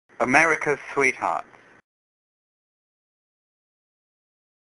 來！讓小丸子陪你邊聊明星，邊練發音，當個真正的追星族！